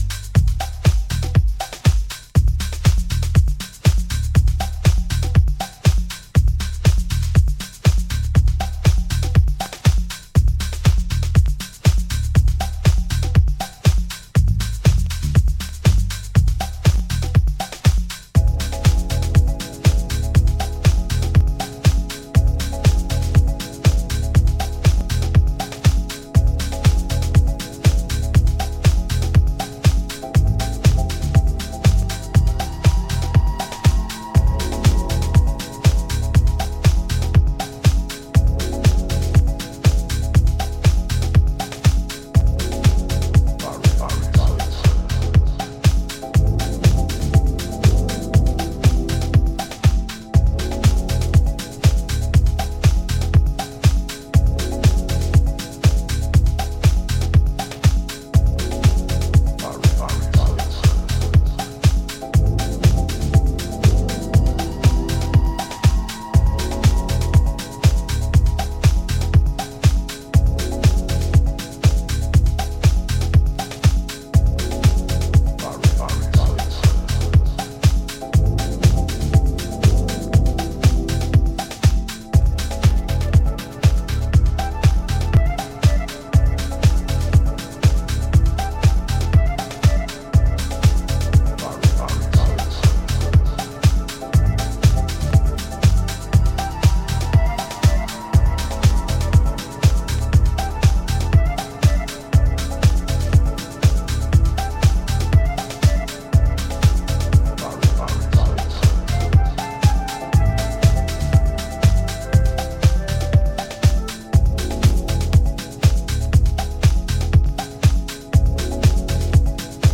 柔らかい浮遊コードやスペーシーなシンセを駆使しながら、じっくりとビートダウン・ハウスを繰り広げています。